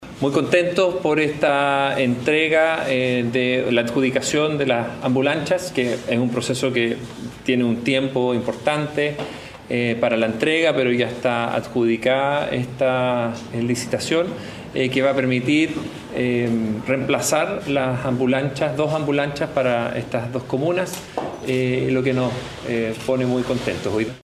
El proyecto establece un plazo de 300 días corridos para la posterior entrega de estas naves, tras lo cual se integrarán al sistema de emergencias del Servicio de Salud Chiloé, según detalló el subsecretario Bernardo Martorell.